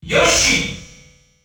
The announcer saying Yoshi's name in French releases of Super Smash Bros.
Yoshi_French_Announcer_SSB.wav